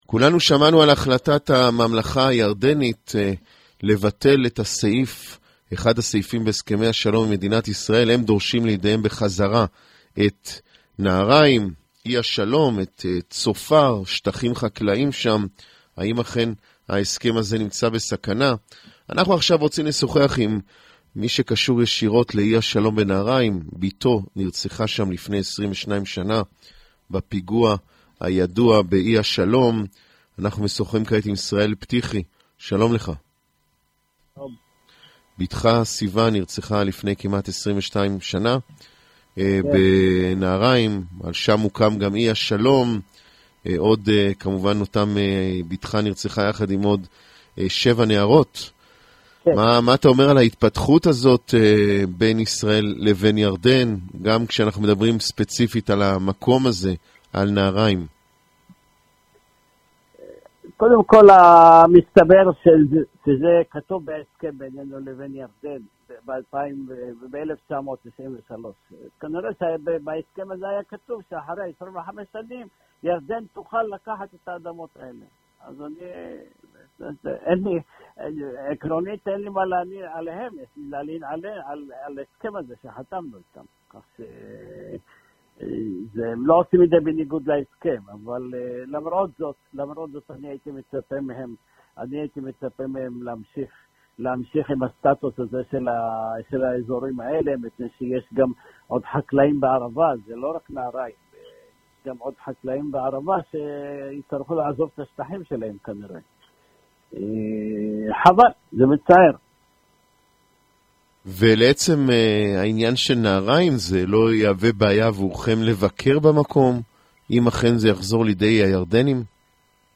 Полностью интервью